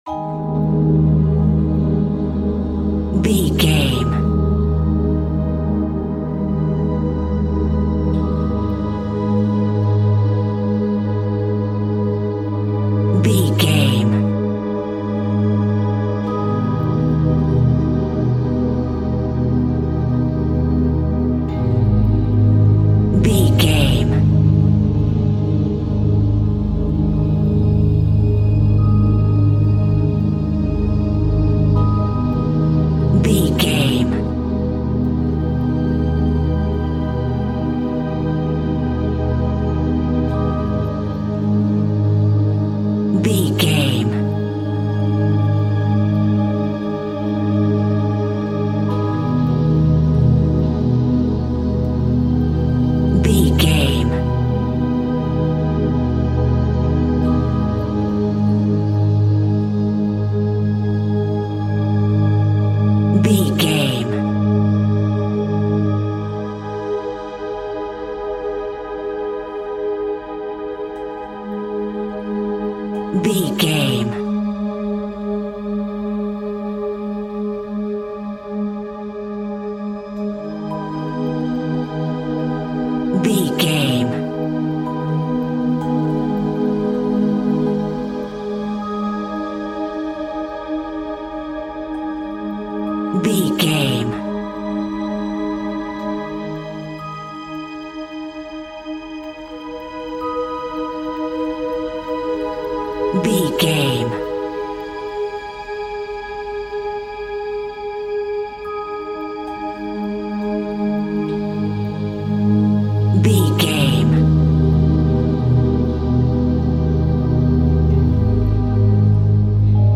Thriller
Aeolian/Minor
G#
Slow
ominous
dark
eerie
synthesiser
strings
electric piano
Horror synth
Horror Ambience